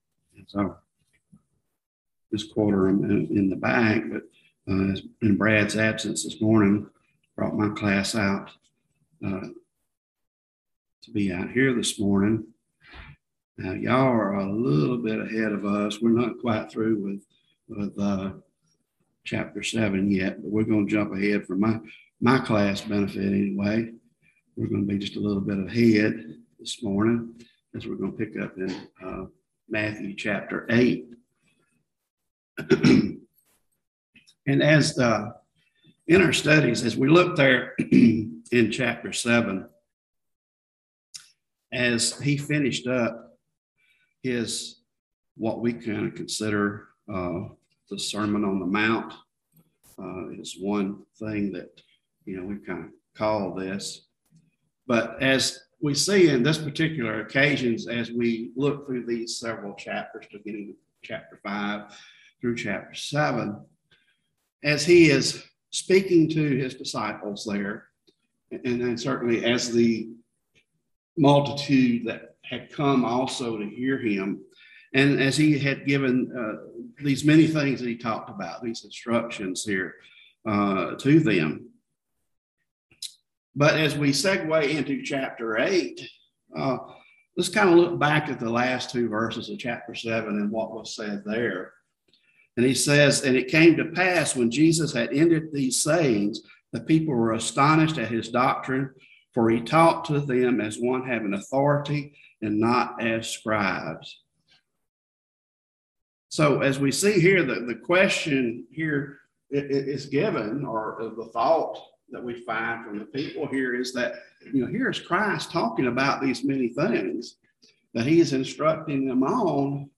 Service Type: Bible Classes